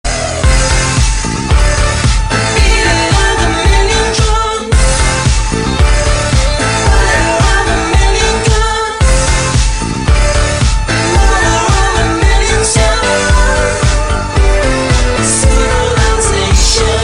civilization-horn.ogg